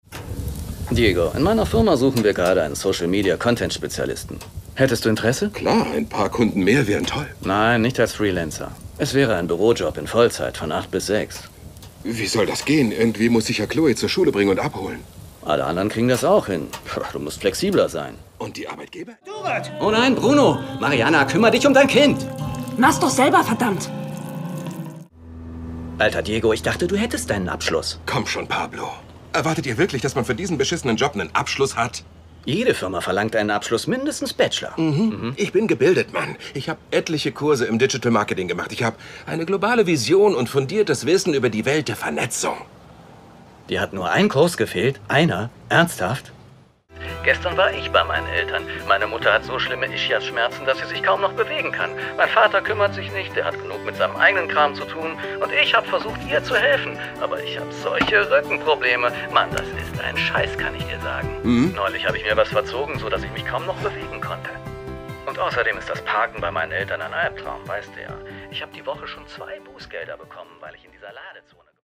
Synchronrolle für Disney-Serie Rolle Pablo in Shared Custody